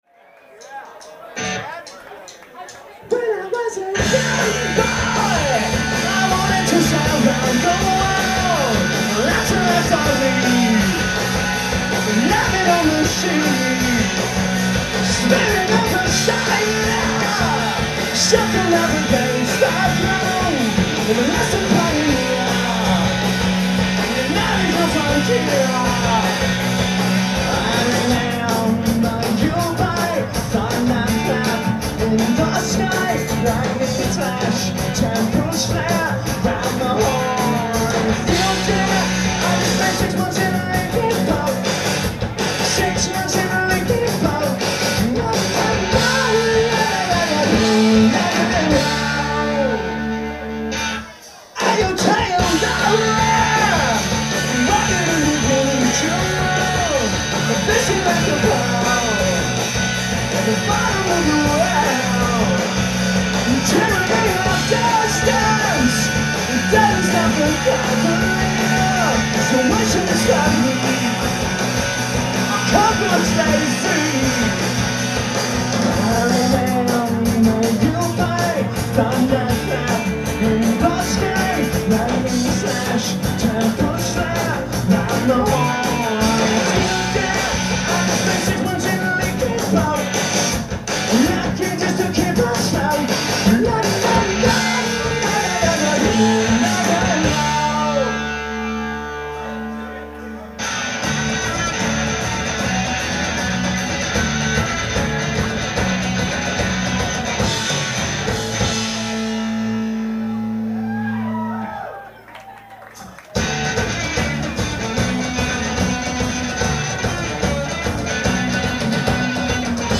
live at the Middle East, Cambridge, MA